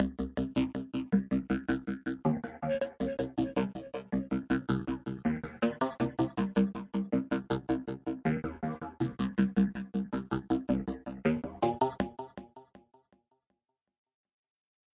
口香糖爆裂
描述：开头部分是谈话，由于无法创造声音，结束是口香糖砰砰声。随机的人来往于这个小区域，门经常打开和关闭。与H4N进行同步
标签： 玄关 氛围 环境 内部的人 牙龈大跌眼镜
声道立体声